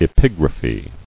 [e·pig·ra·phy]